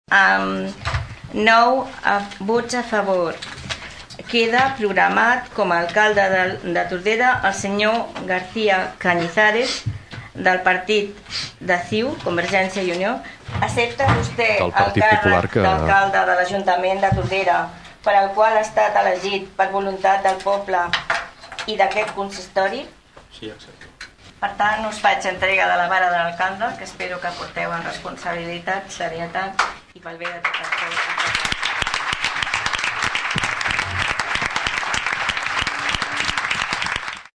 L’auditori del Teatre Clavé s’ha omplert de gom a gom durant en Ple de constitució del nou ajuntament.